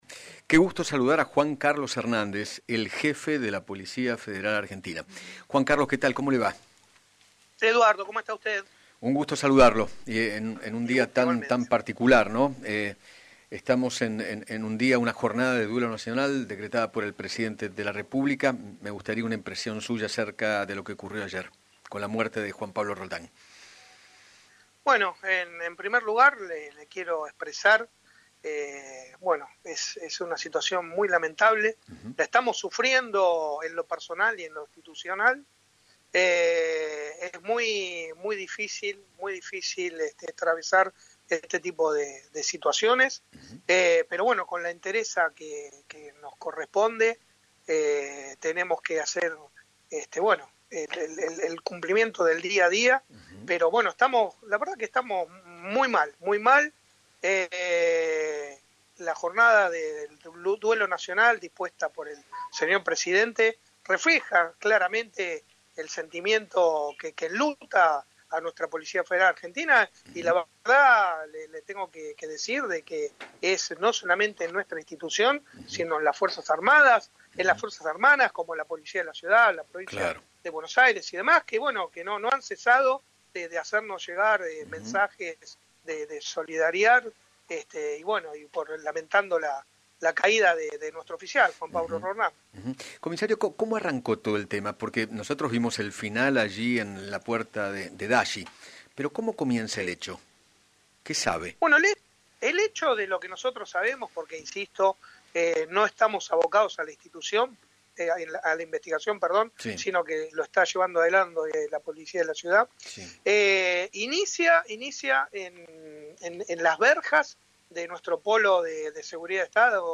Juan Carlos Hernández, jefe de la policía federal, conversó con Eduardo Feinmann sobre la trágica tarde que se vivió ayer en Palermo, luego de que un policía fuera apuñalado por un hombre de 51 años, quien falleció horas después por los disparos recibidos por parte del oficial. Además, se sumó al debate del uso de las Taser.